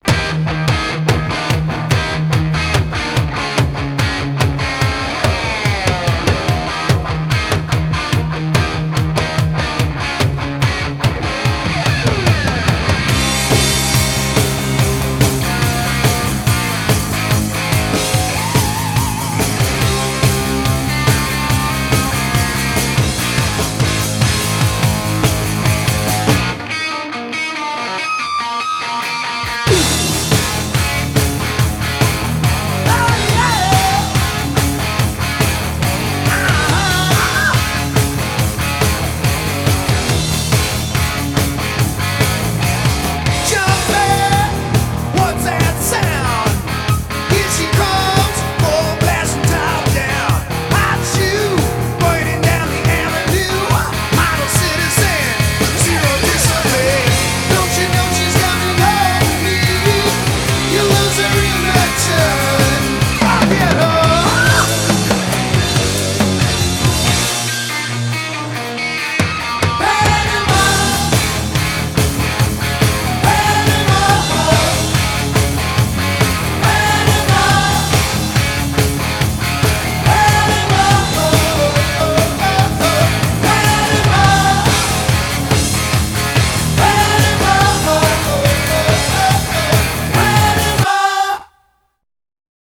BPM100-152
Audio QualityMusic Cut